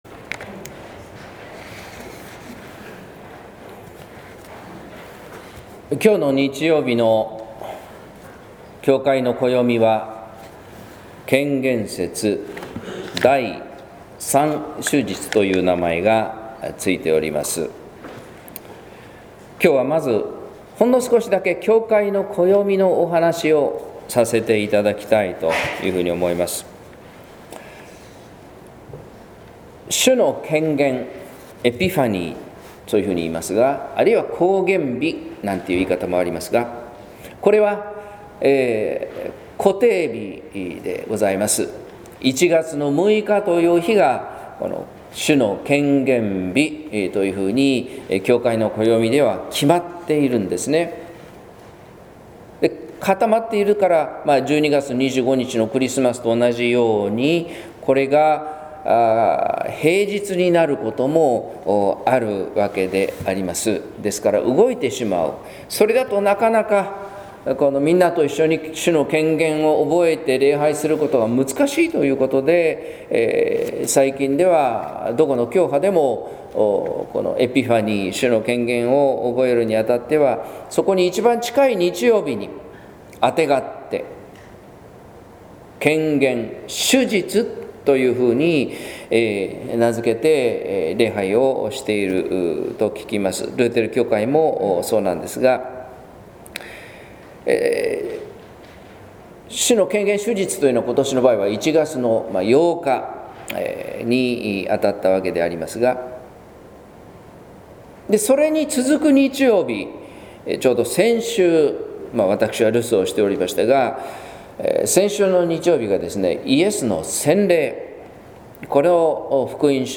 説教「神の国のロジック」（音声版）